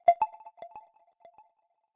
Звуки телефона Huawei
Вы можете слушать онлайн и скачивать стандартные рингтоны, мелодии будильника, оповещения о сообщениях и системные сигналы.